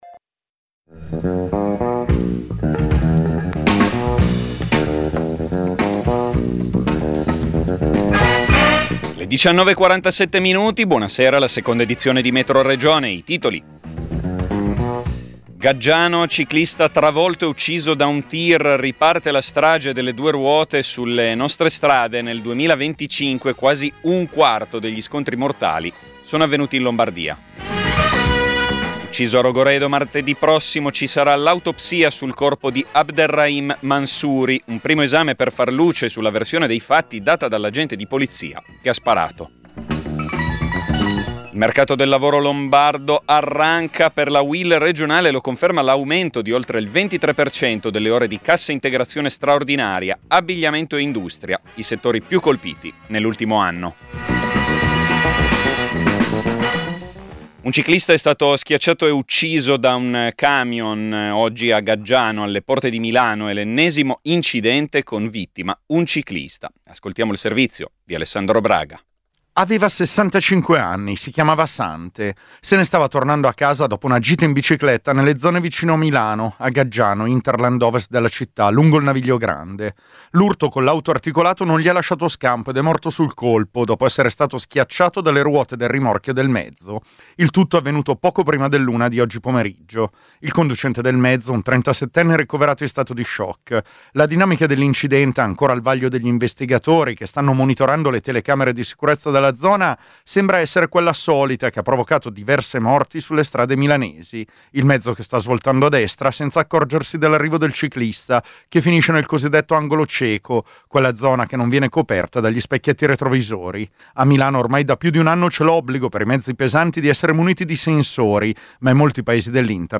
Metroregione è il notiziario regionale di Radio Popolare. Racconta le notizie che arrivano dal territorio della Lombardia, con particolare attenzione ai fatti che riguardano la politica locale, le lotte sindacali e le questioni che riguardano i nuovi cittadini.